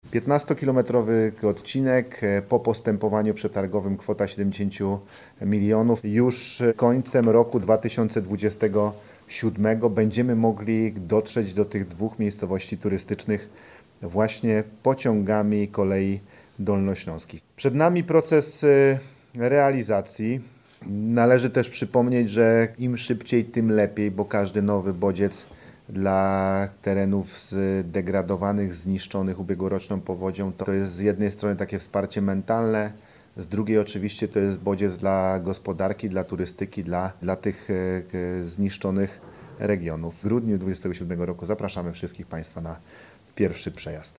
–  Już za 24 miesiące dojedziemy do Stronia Śląskiego pociągami Kolei Dolnośląskich – dodaje marszałek.